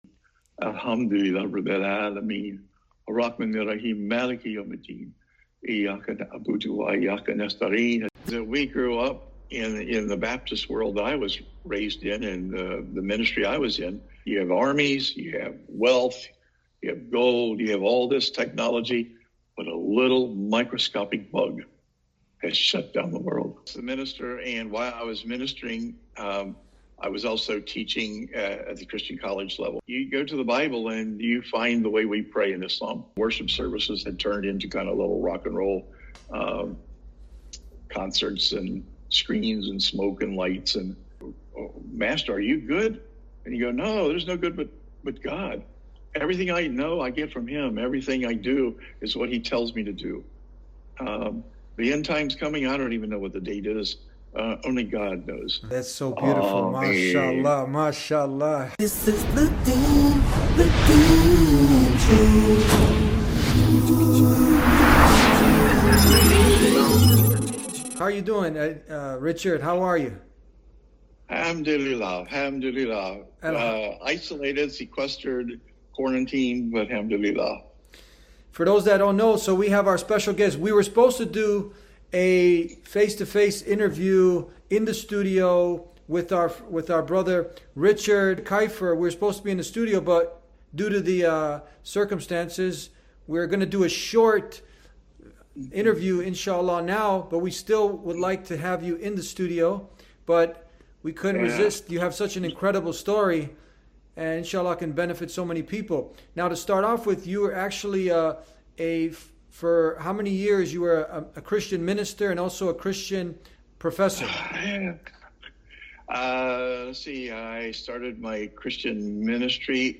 In this enlightening episode of The Deen Show, the guest, a former Christian minister and college professor, shares his transformative journey that led him to embrace Islam.